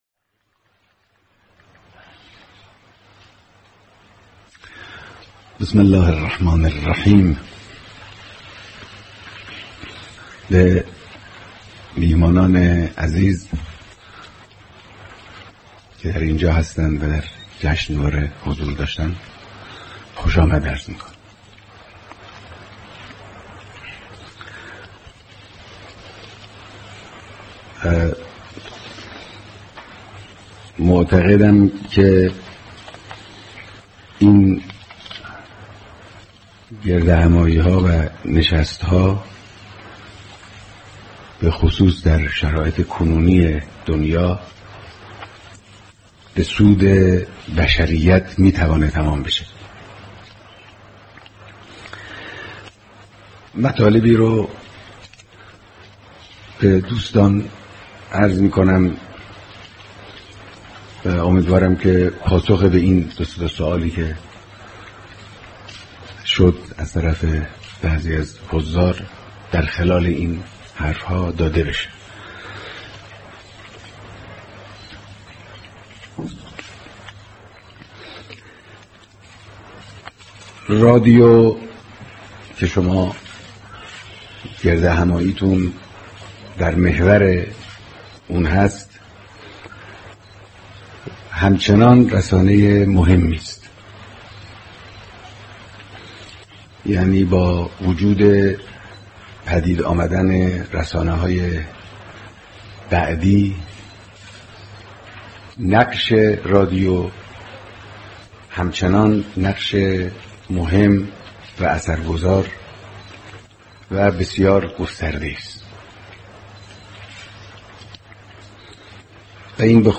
صوت کامل بیانات